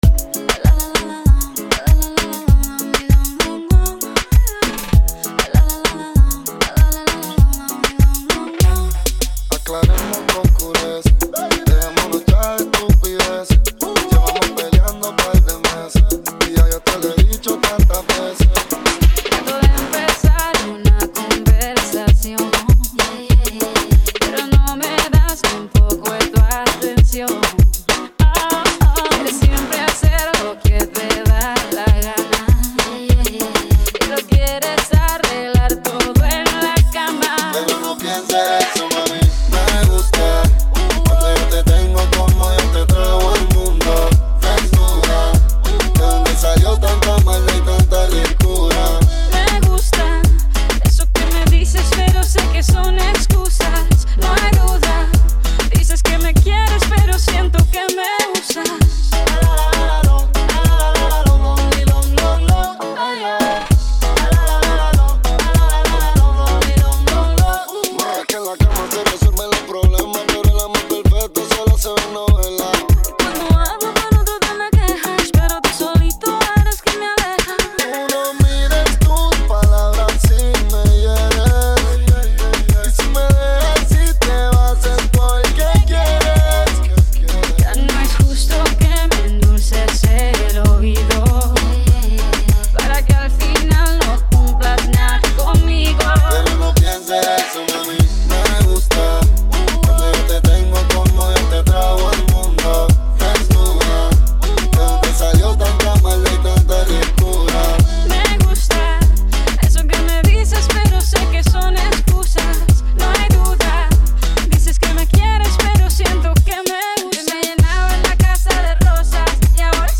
Reggaeton Mix
98 Bpm Mp3 Download